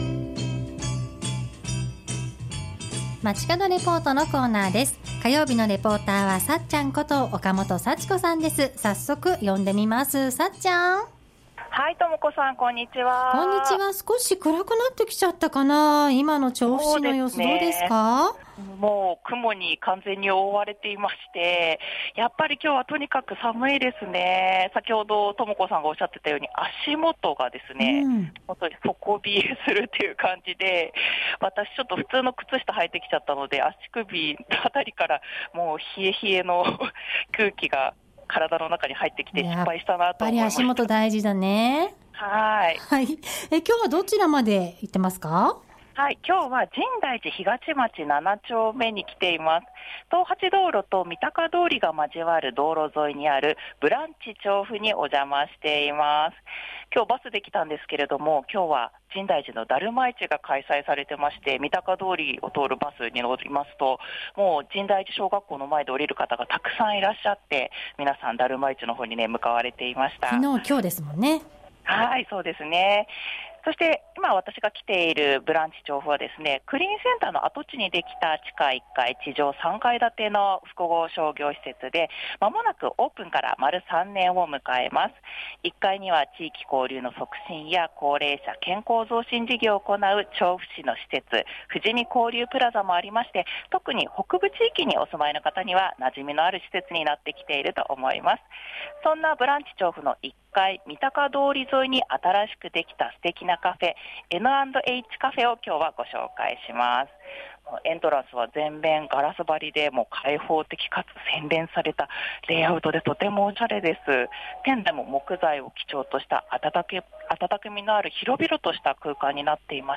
午後のカフェテラス 街角レポート
中継は深大寺東町7丁目にある「ブランチ調布」から。